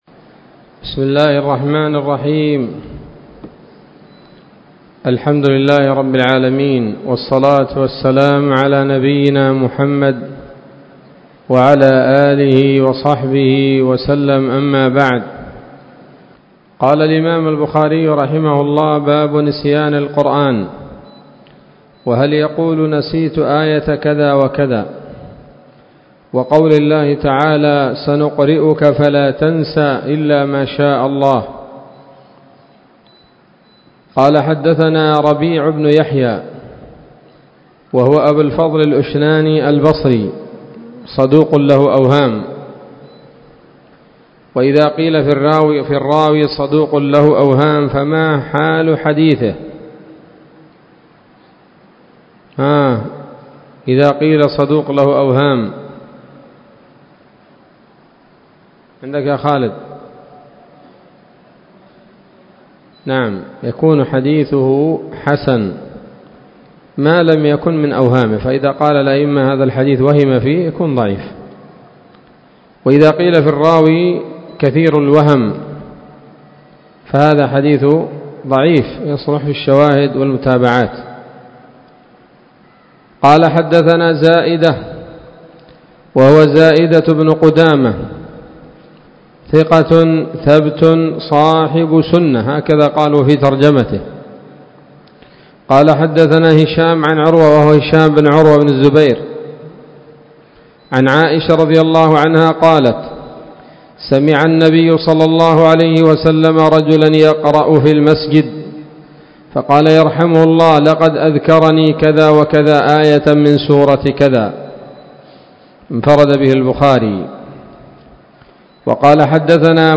الدرس السابع والعشرون من كتاب فضائل القرآن من صحيح الإمام البخاري